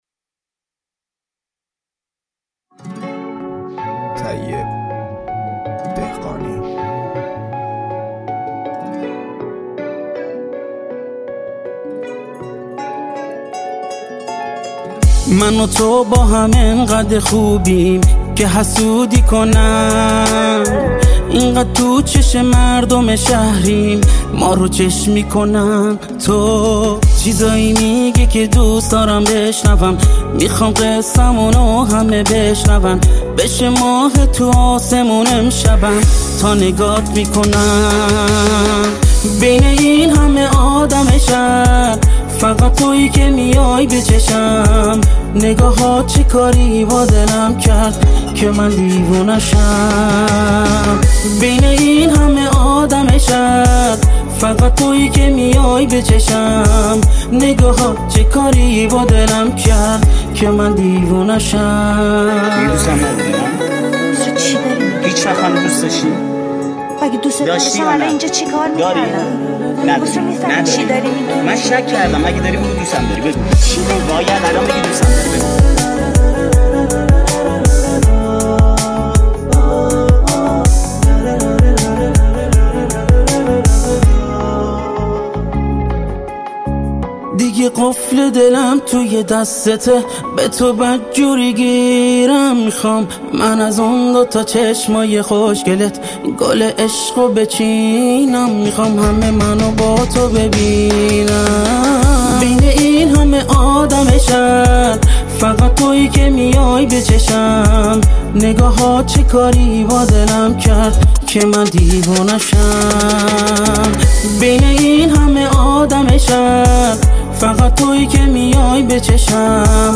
خیلی با لحجه خونده و درست با کلمات و احساس ارتباط نگرفته